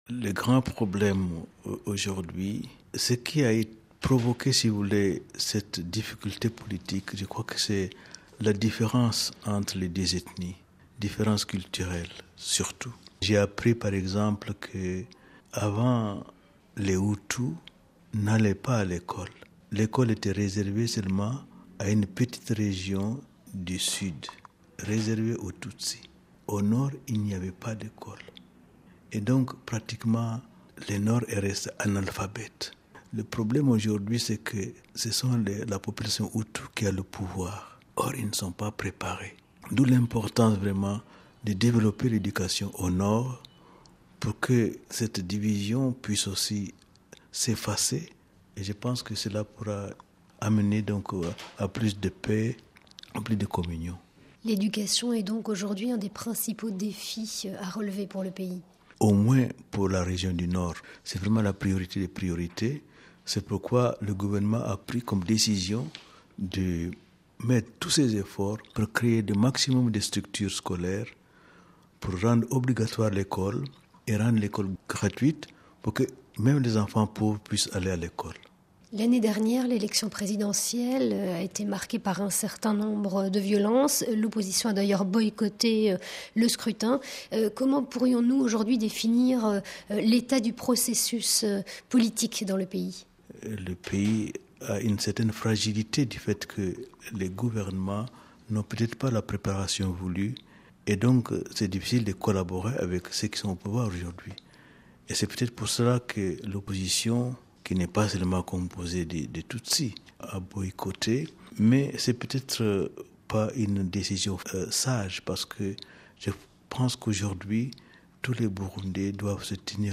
Entretien avec le cardinal Sarah de retour du Burundi